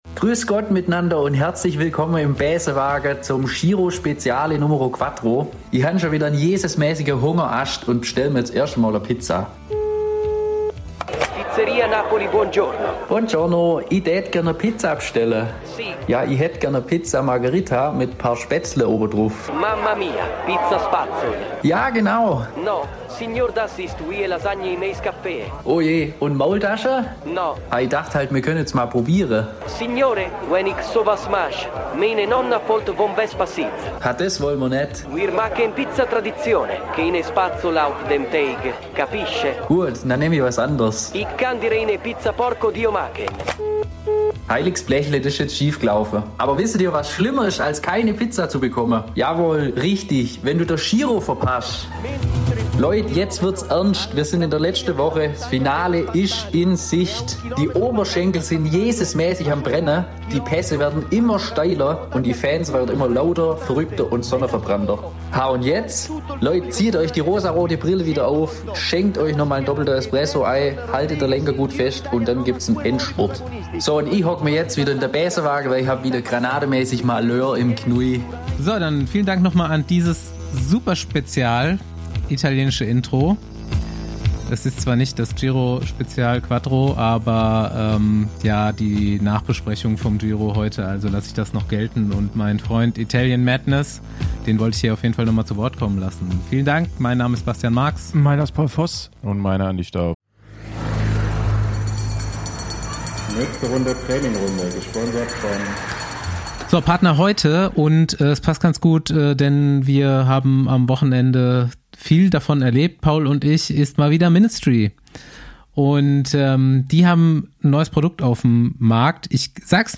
Mit wechselnden Gästen geht es dabei neben dem Sport auch mal um ganz alltägliche Dinge.